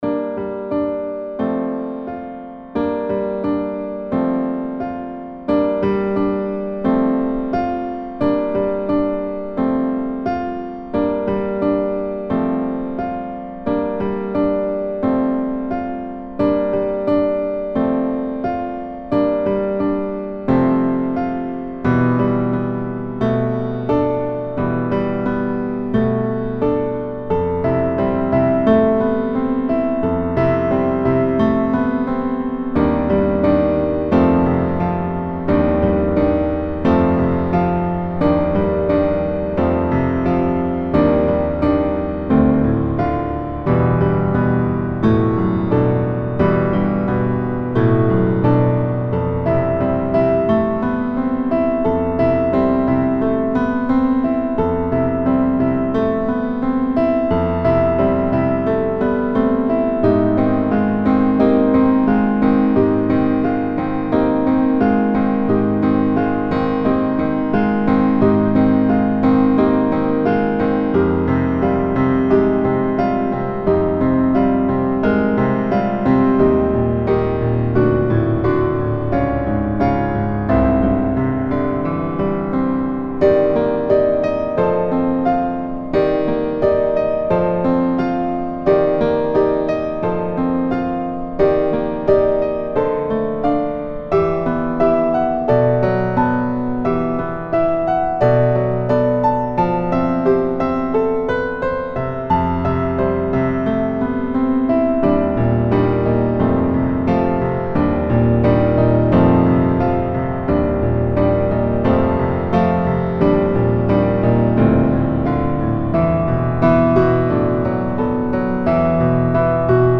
自制karaoke
非消音，听写的。